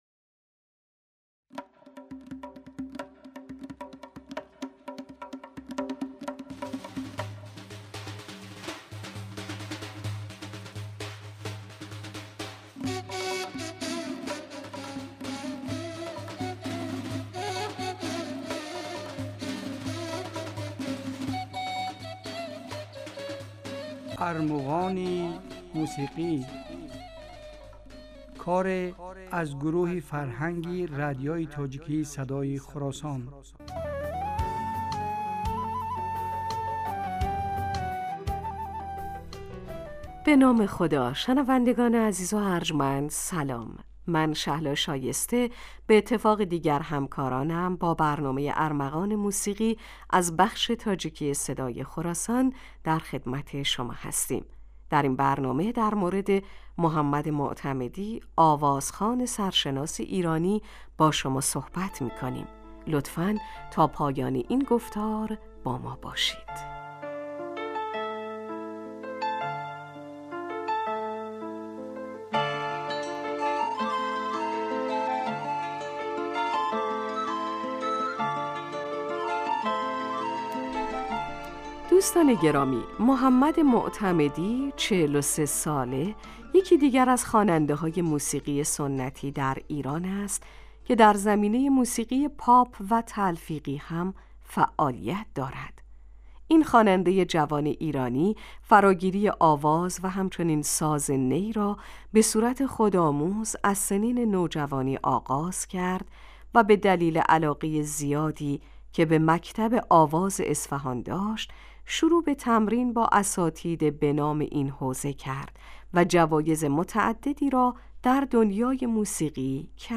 Армуғони мусиқӣ асари аз гурӯҳи фарҳанги радиои тоҷикии Садои Хуросон аст.